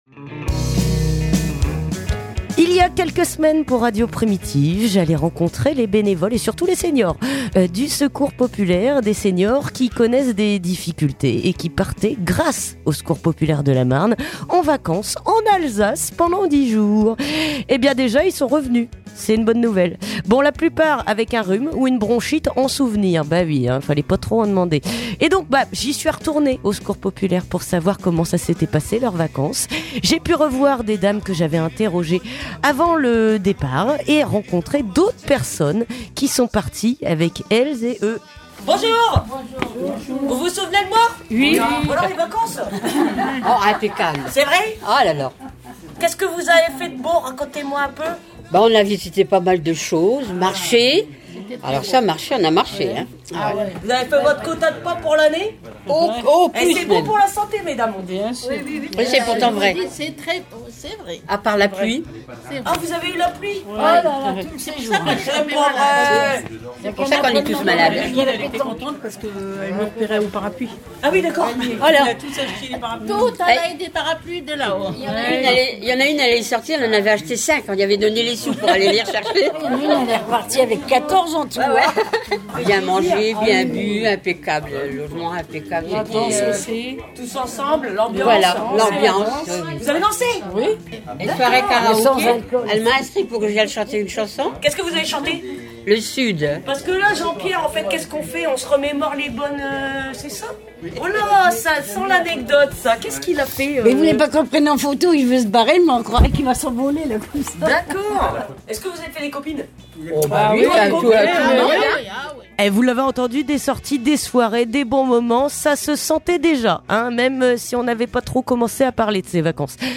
Reportage au Secours Populaire de la Marne